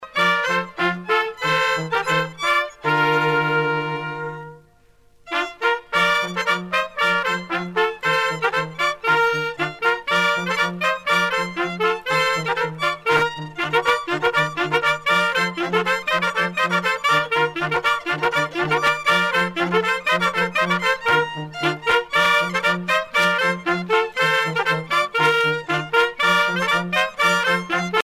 danse : gigouillette
groupe folklorique
Pièce musicale éditée